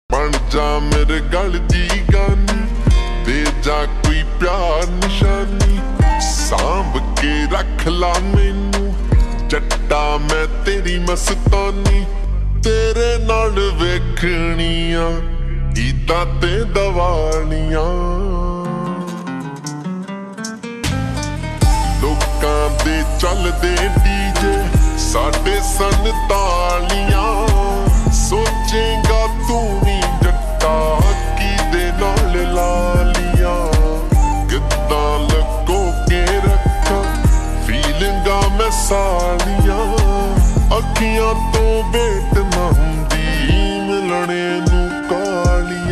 Punjabi Songs
(Slowed + Reverb)